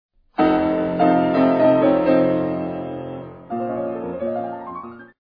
Beispiel: Dominantseptnonenakkord
Frédéric Chopin, aus: 24 Préludes, op. 28, Nr. 16, b-moll
Die kleine None ("ges") wird hier nicht direkt zur Tonika-Quint sondern zunächst als Vorhalt zum Dominant-Grundton und dann weiter abwärts bis zur Dominantquint geführt.